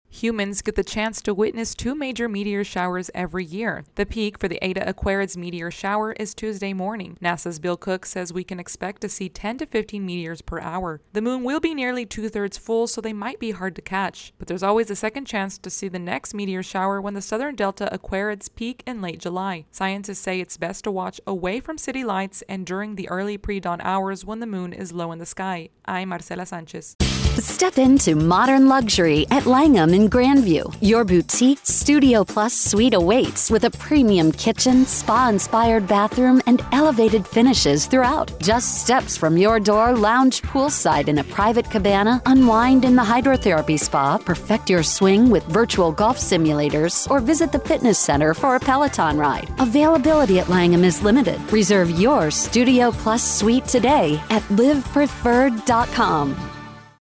In just a few days, Earth will experience a meteor shower. AP correspondent